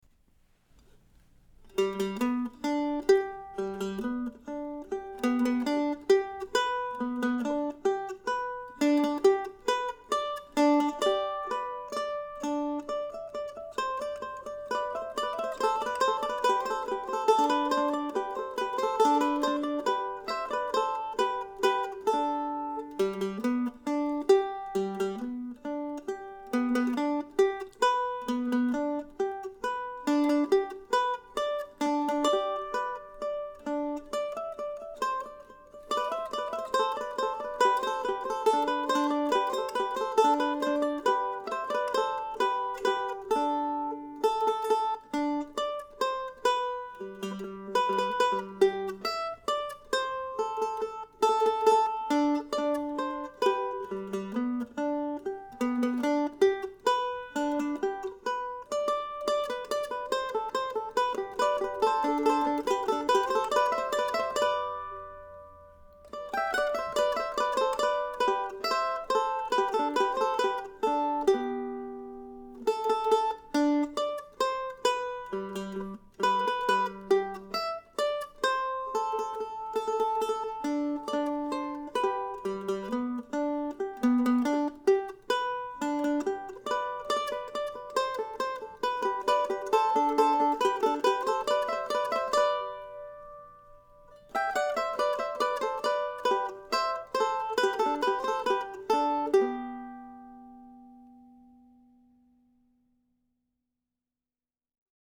Bates no. 10 was more challenging than most of its relatives and so this recording is more demo-quality than most.